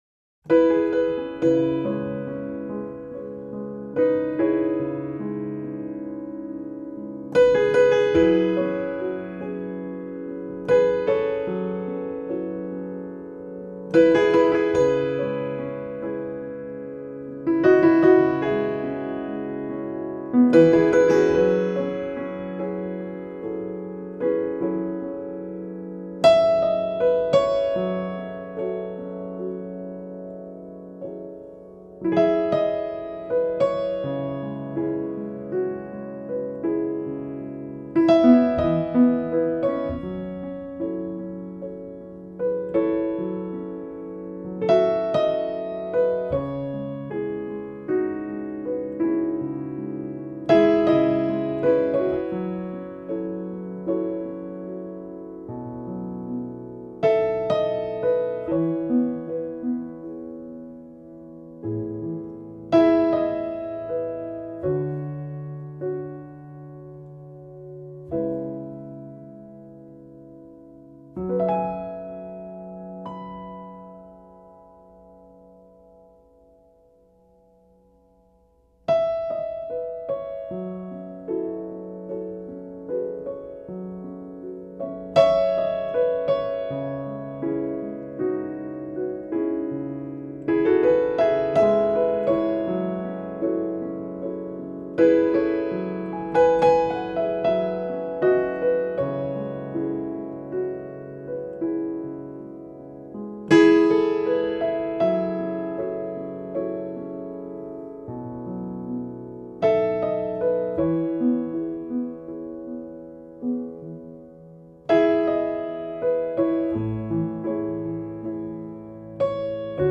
音乐类型： New Age
PIANO
而曲中以简单和弦所构筑出的清澈氛围